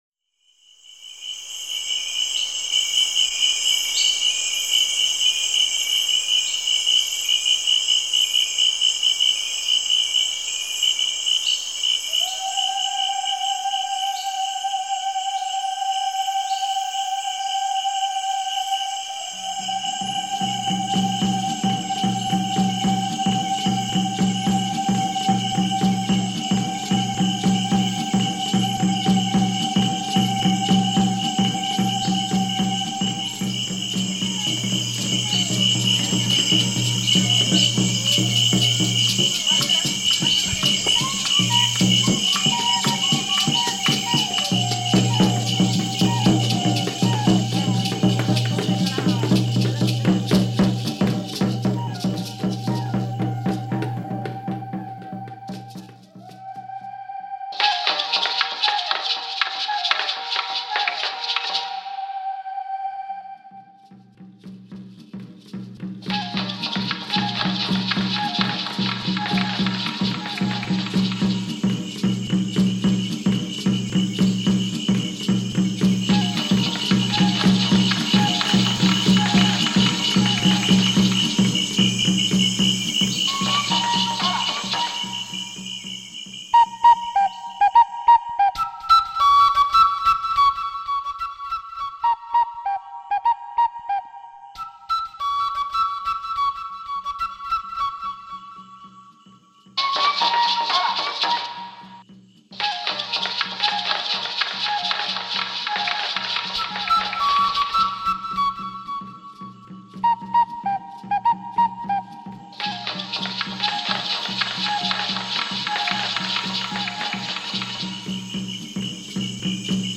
I chose a piece which included a wind instrument and so it became a natural thing to highlight that as part of my piece.
After listening extensively to the recording I found myself always wanting to move my feet – it was very much a dancing rhythm with constant movement.